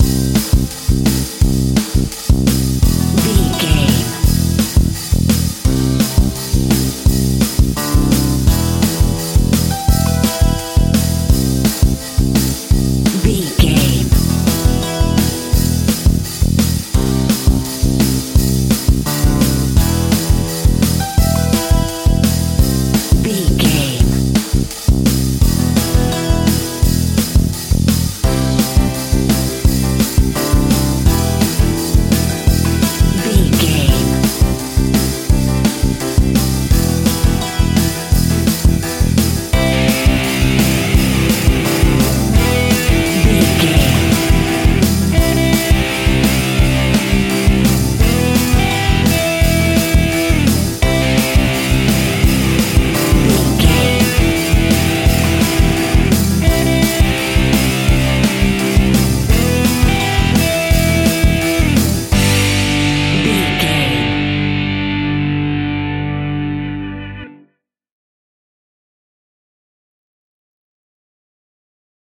royalty free music
Epic / Action
Fast paced
Aeolian/Minor
pop rock
indie pop
fun
energetic
uplifting
cheesy
instrumentals
guitars
bass
drums
piano
organ